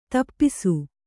♪ tapisu